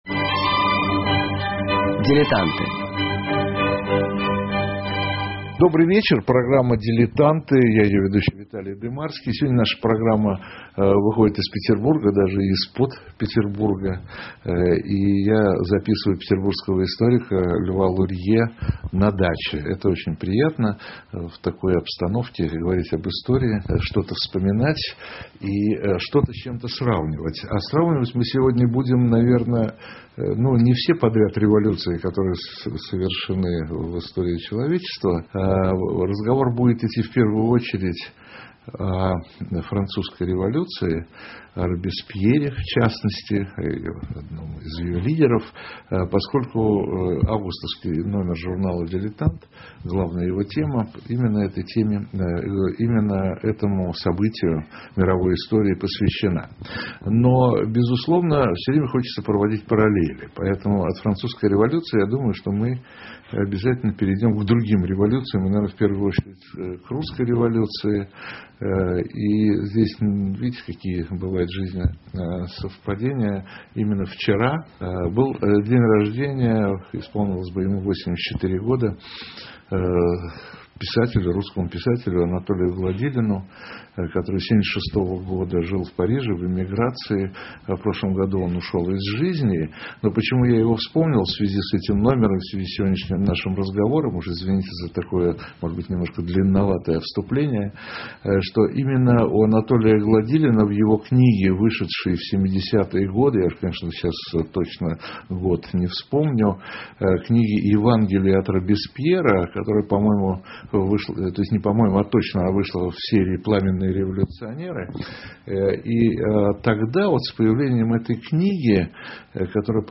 Сегодня наша программа выходит из Петербурга, даже из-под Петербурга, и я записываю петербуржского историка, Льва Лурье на даче, это очень приятно, в такой обстановке говорить об истории, что-то вспоминать и что-то с чем-то сравнивать.